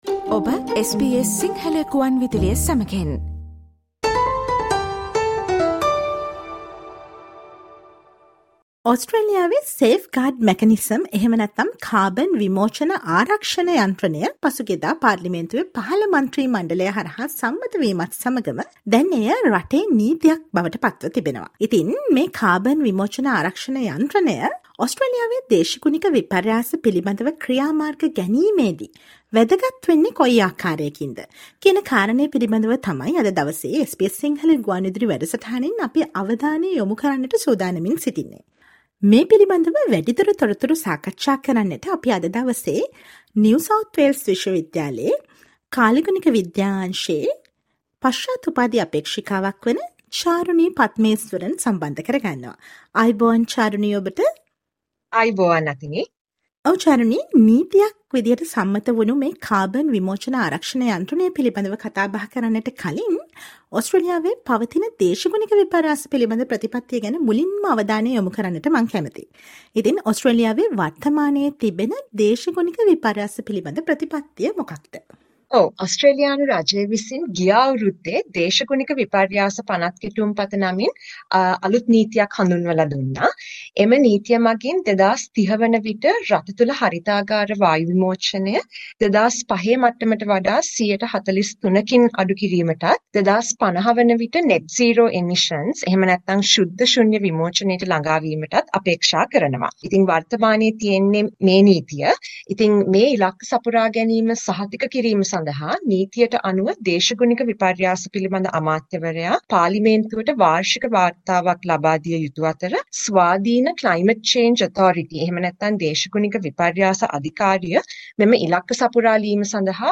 Listen to the SBS Sinhala radio interview on the importance of "safegurad machanism" with regards to the climate action of Australia.